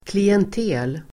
Ladda ner uttalet
klientel substantiv (ofta nedsättande), clientele [often pejorative]Uttal: [klient'e:l] Böjningar: klientelet, klientel, klientelenDefinition: grupp klienter, kundkrets